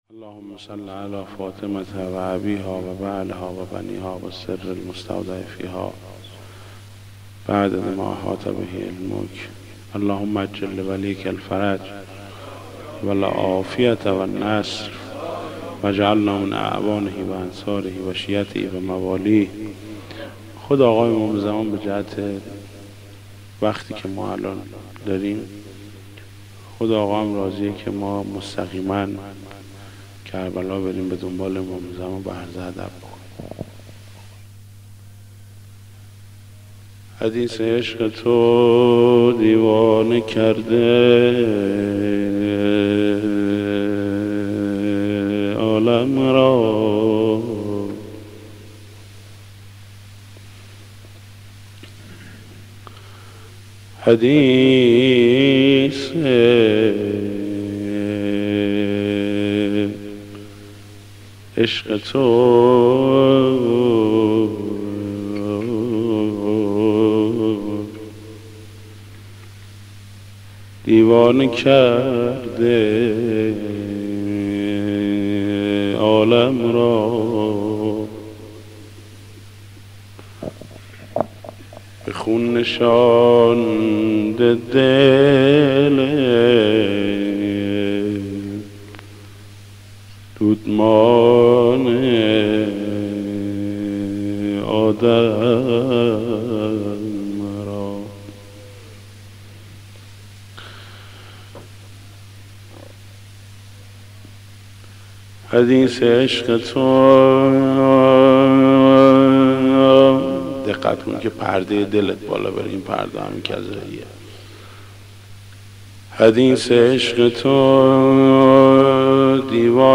مداح
مناسبت : شب چهارم محرم
مداح : محمود کریمی